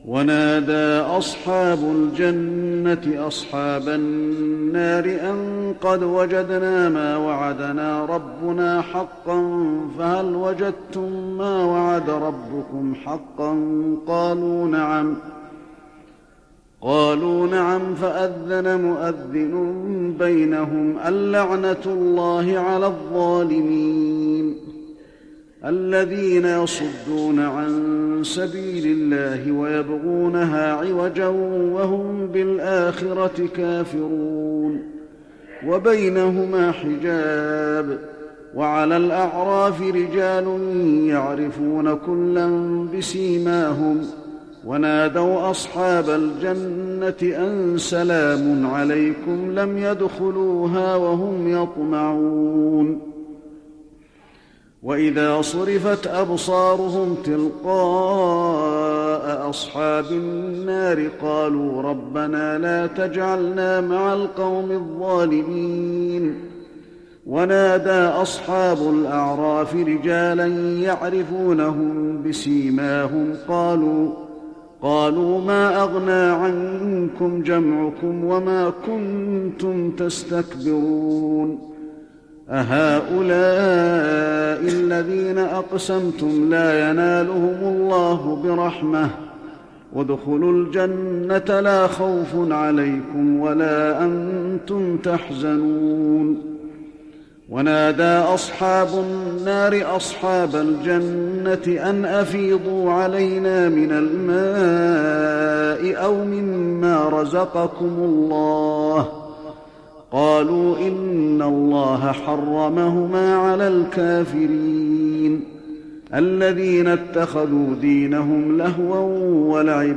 تراويح رمضان 1415هـ من سورة الأعراف (44-151) Taraweeh Ramadan 1415H from Surah Al-A’raf > تراويح الحرم النبوي عام 1415 🕌 > التراويح - تلاوات الحرمين